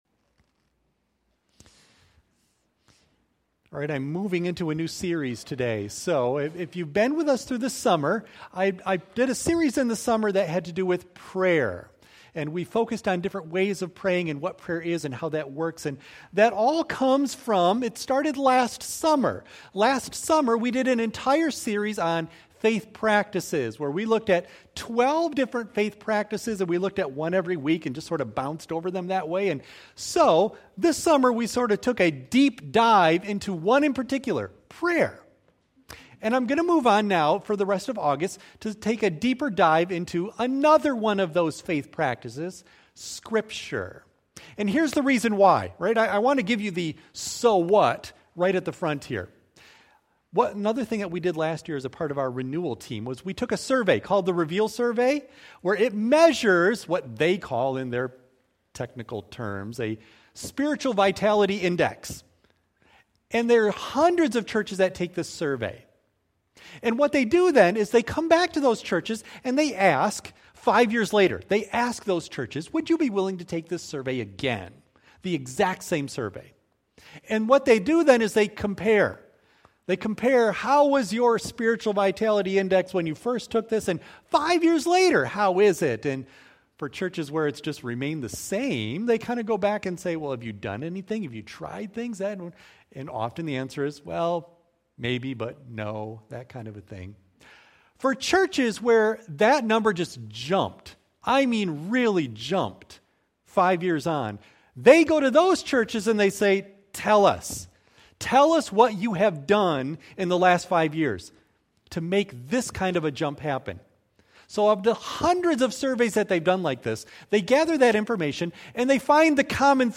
Worship Service
Audio of Message